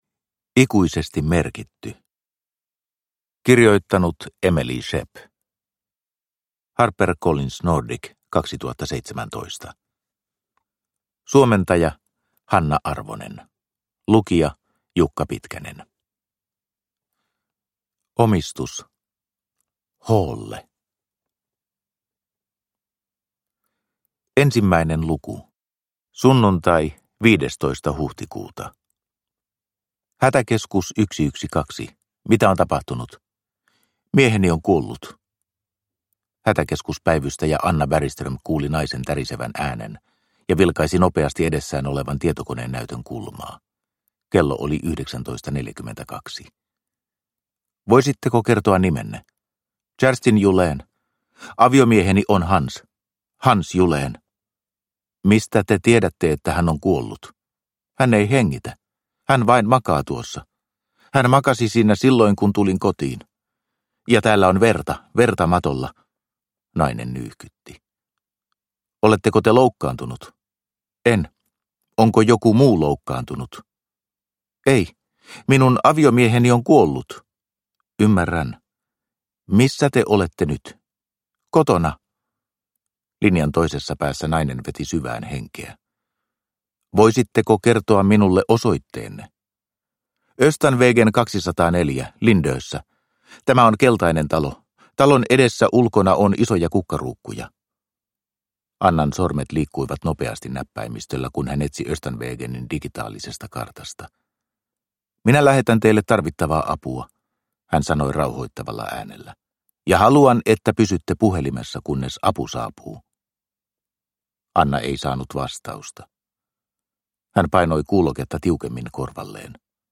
Ikuisesti merkitty – Ljudbok – Laddas ner